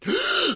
MGasp1.ogg